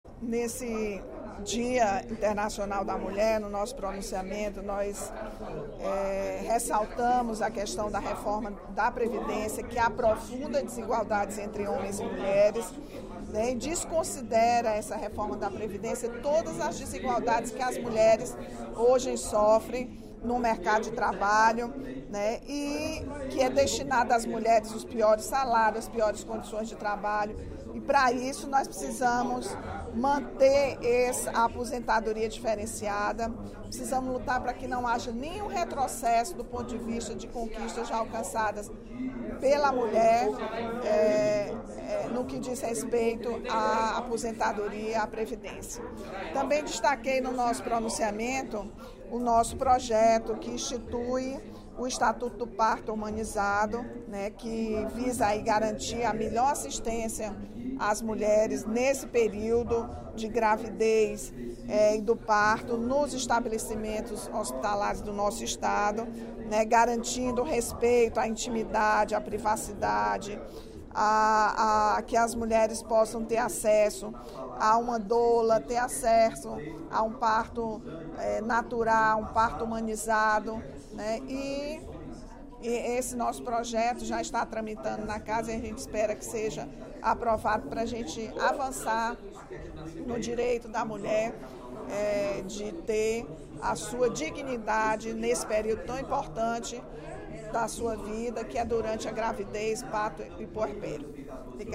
A deputada Rachel Marques (PT) destacou, nesta quarta-feira (08/03), durante o primeiro expediente da sessão plenária, a questão da Reforma da Previdência, proposta pelo Governo Federal, que estipula a mesma idade mínima para a aposentadoria a homens e mulheres.